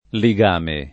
[ li g# me ]